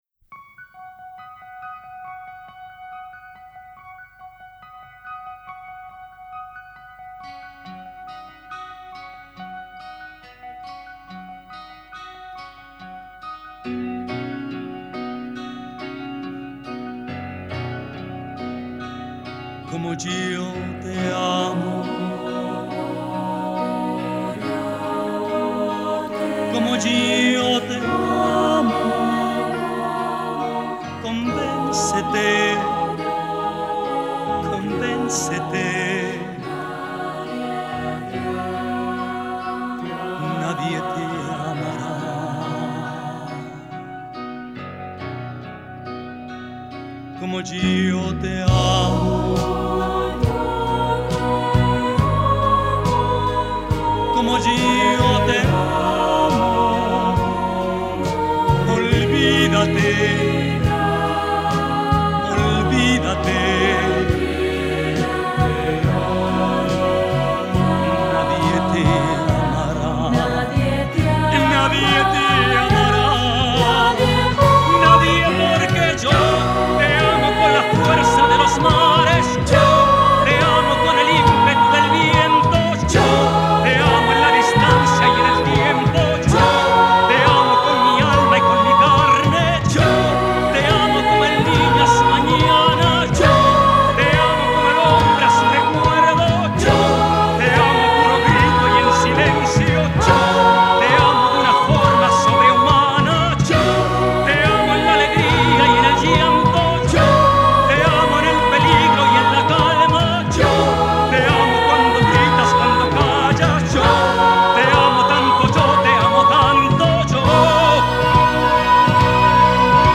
Orfeón Fermín Gurbindo (archivo mp3), las corales Cidade de Vigo, Alaia y